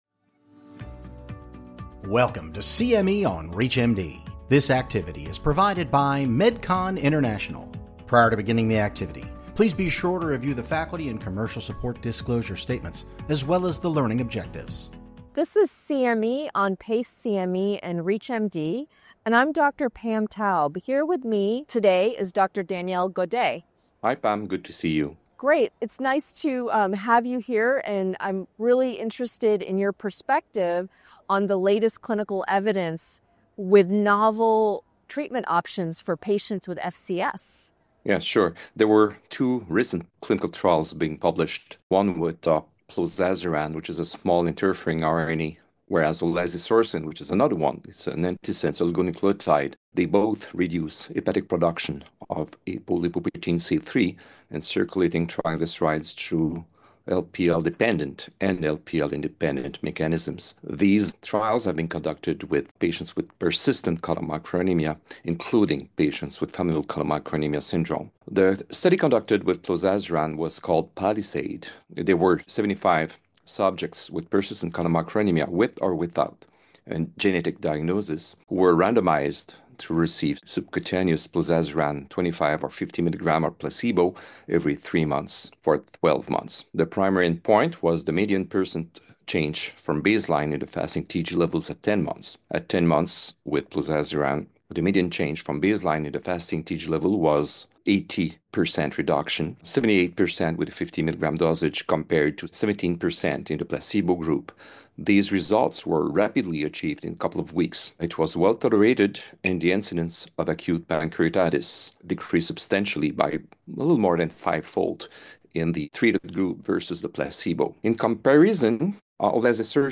Recent developments on APOC3 inhibition show promising results for the management of these patient groups. Four experts discuss the characteristics, diagnostic criteria and the risks of patients with FCS, SHTG, and mixed hyperlipidemia, and review the recent evidence of clinical trials with new APOC3 inhibitors.